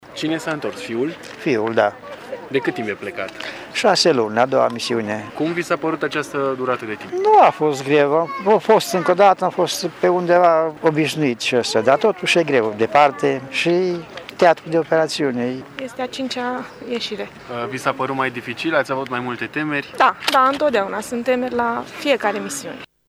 Cele mai fericite de întoarcerea militarilor au fost familiile acestora: